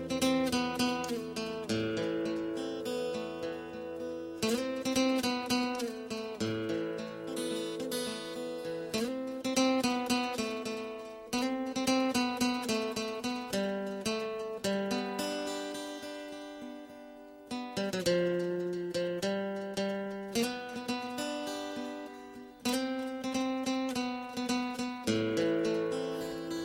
Guitar Ringtones